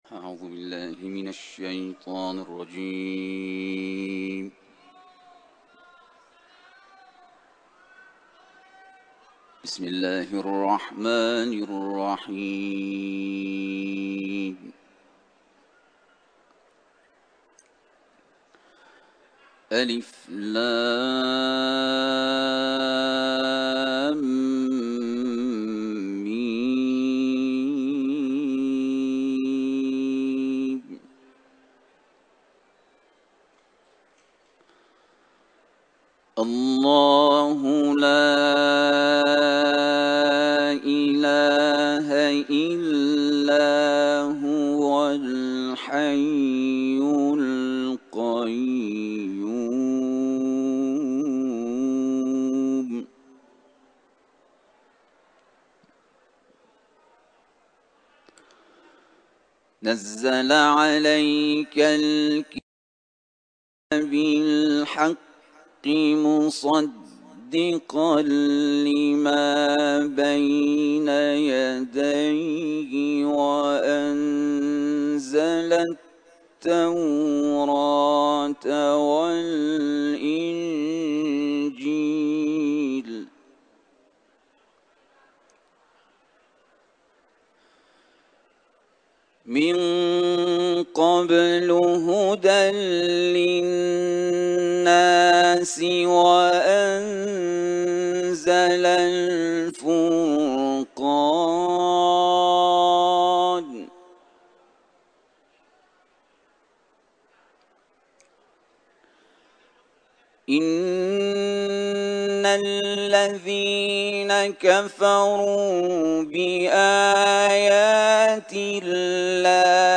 Kur’an tilaveti